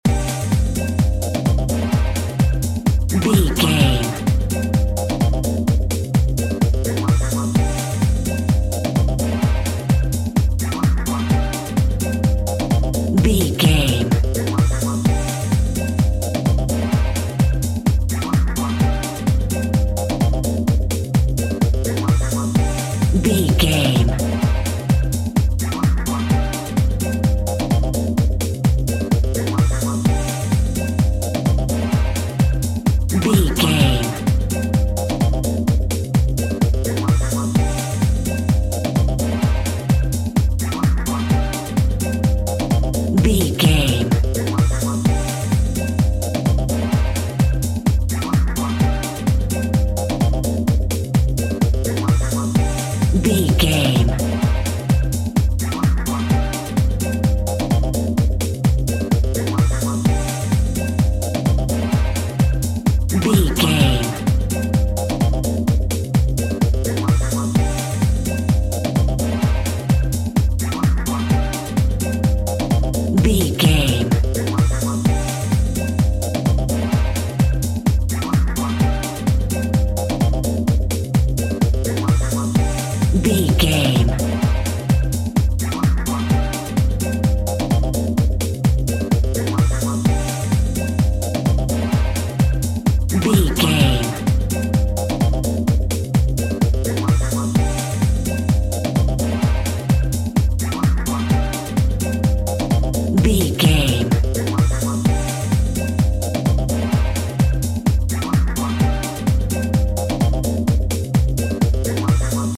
Holiday House Music Cue.
Aeolian/Minor
funky
groovy
uplifting
futuristic
driving
energetic
strings
bass guitar
synthesiser
electric piano
drums
electro house
synth drums
synth leads
synth bass